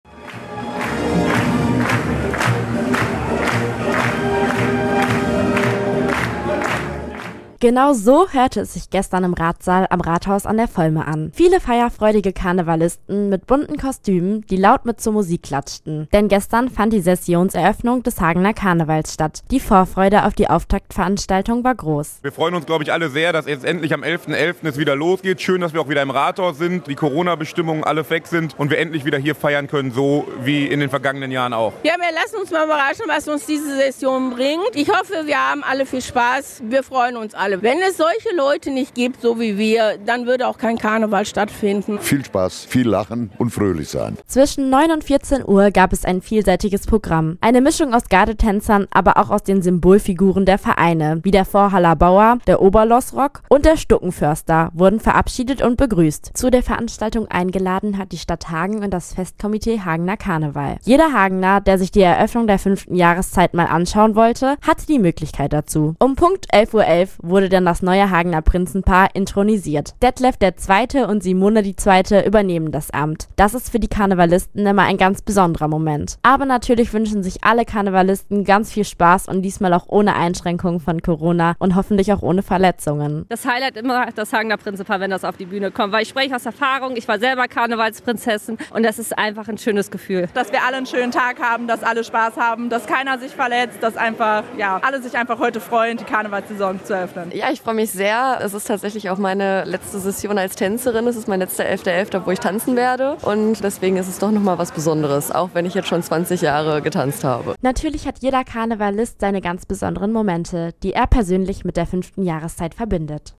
Am 11.November hat die närrische Sessionseröffnung im Rathaus an der Volme stattgefunden.